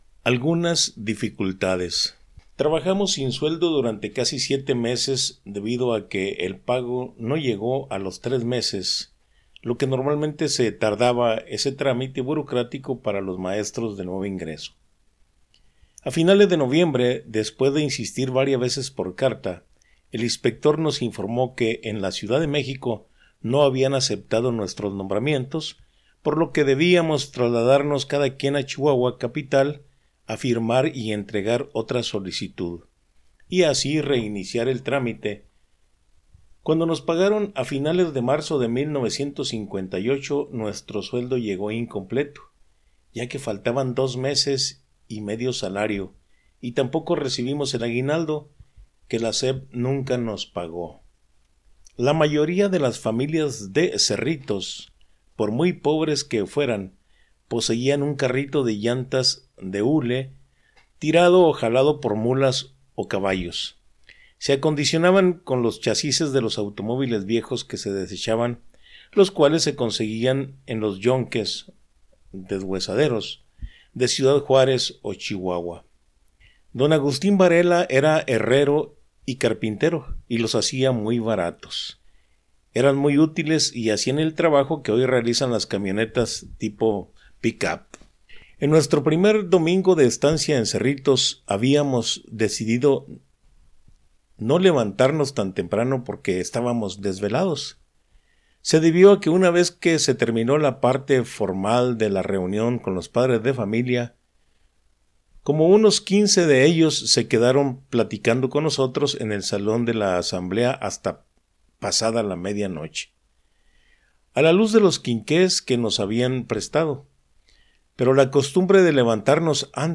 AUDIOLIBRO